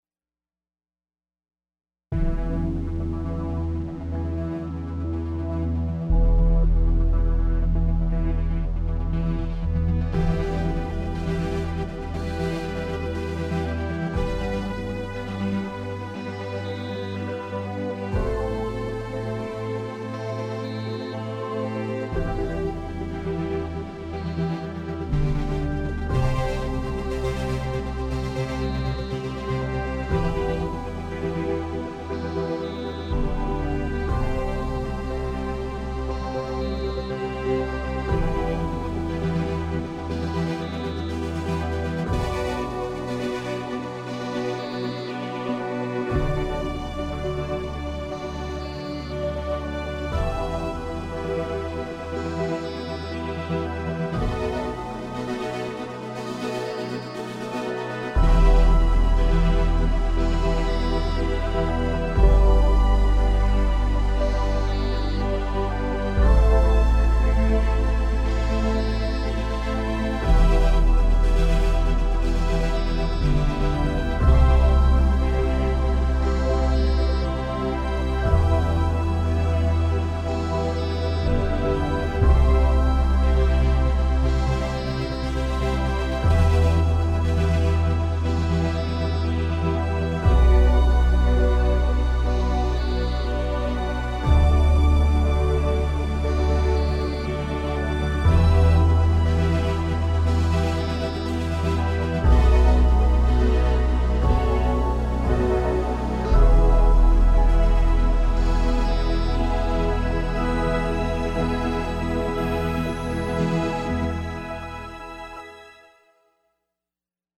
jazz, classical, rock
Trumpet, Rhythm Guitar, Vocals
Tenor Saxophone, Backing Vocals
Lead Guitar
Keyboard